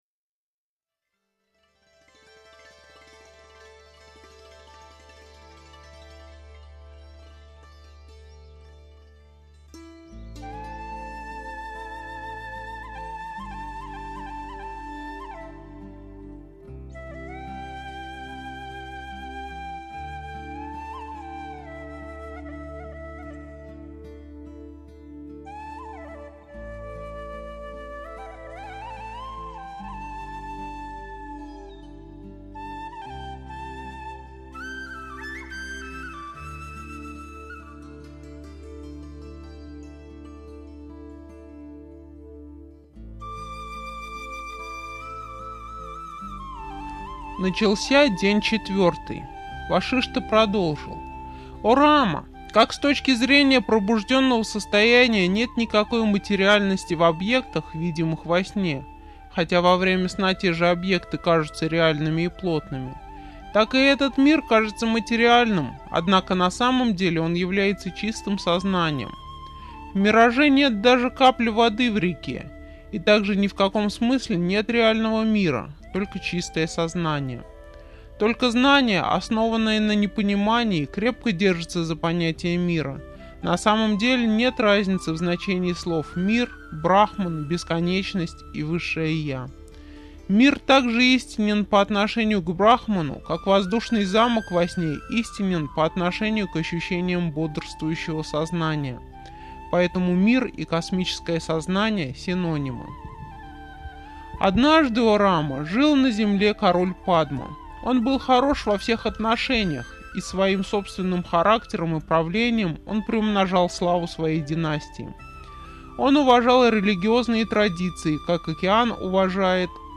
Автор Священное писание из аудиокниги "Йога Васиштха".